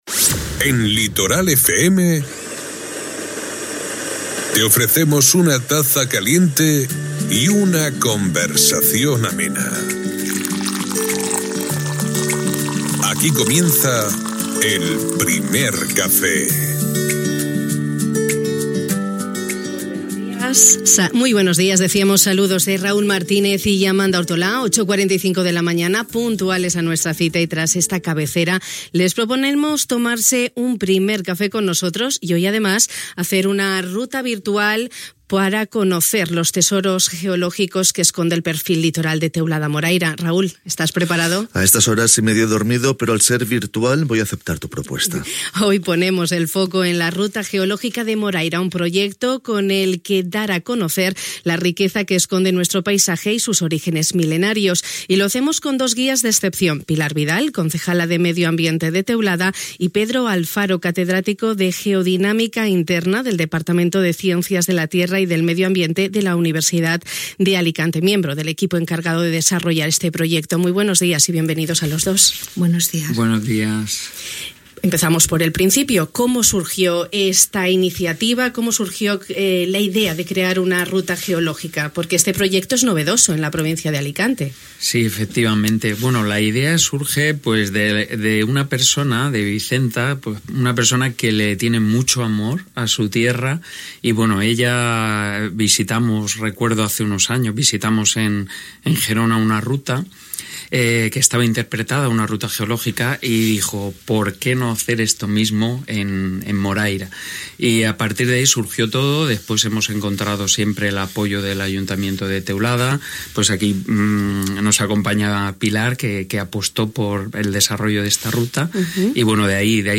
Hui al Primer Café de Ràdio Litoral ens hem anat de ruta, encara que d’una manera virtual. Sense moure’ns dels nostres estudis hem pogut recórrer i conéixer la rica diversitat geològica i natural del front litoral de Teulada, gràcies al projecte: Ruta Geològica de Moraira.